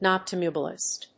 音節noc・tám・bu・list 発音記号・読み方
/‐lɪst(米国英語)/